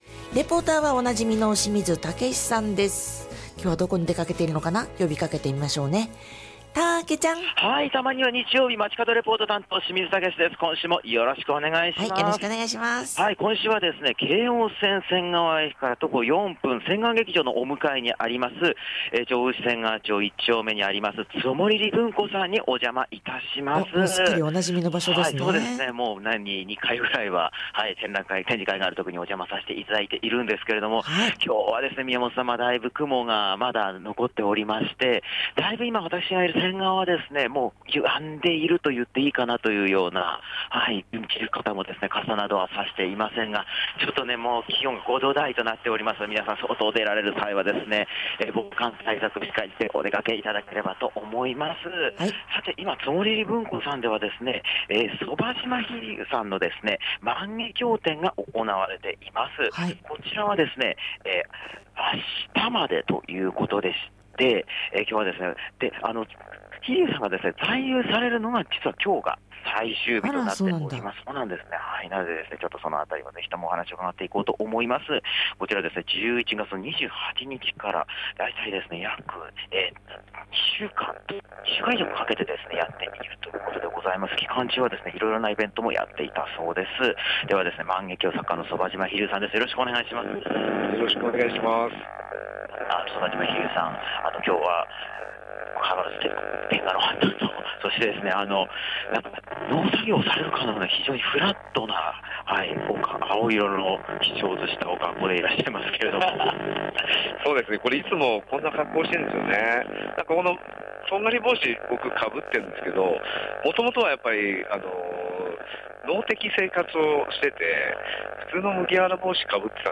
(本日のレポート中、機材の不具合によりお聞き苦しい点がありました事お詫び申し上げます)